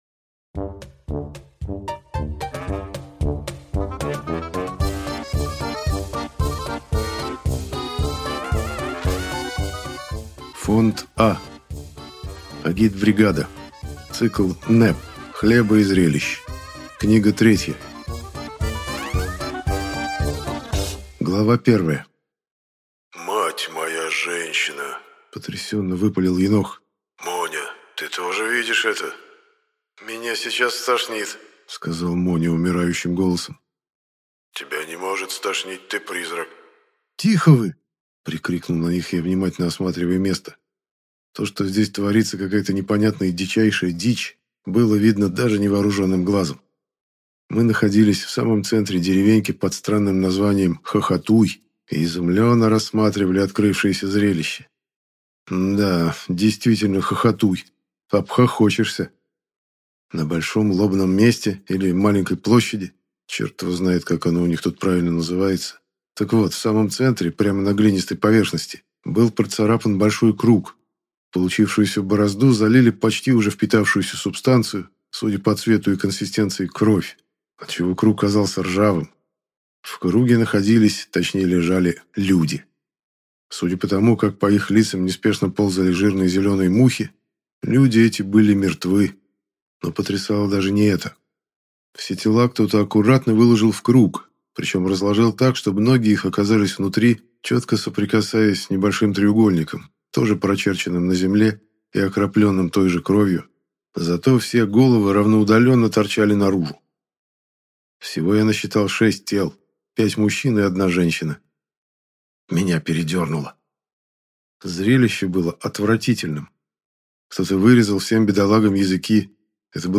Графиня де Монсоро (слушать аудиокнигу бесплатно) - автор Александр Дюма